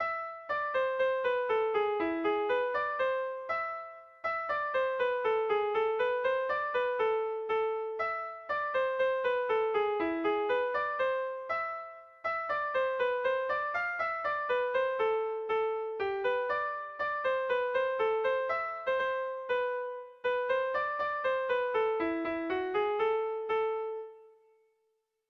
Otxandio < Arratia-Nerbioi < Bizkaia < Basque Country
A1A2A1BDE